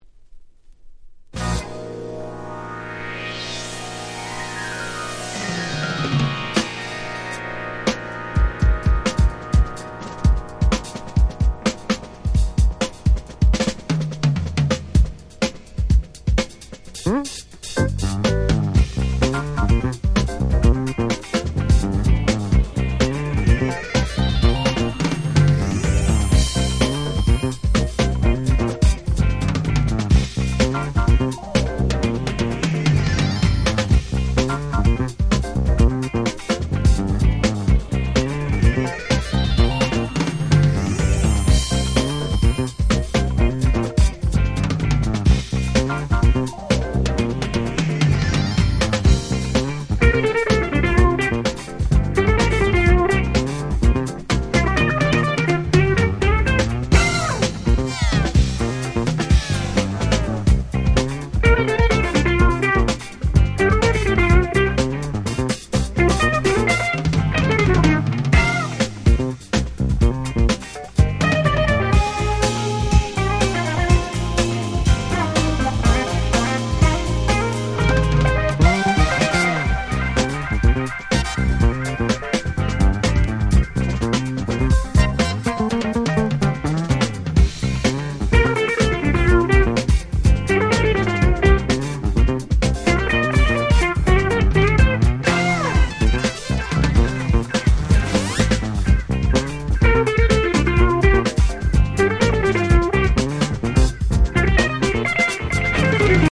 21' Very Nice Re-Edit !!
Jazz ジャズ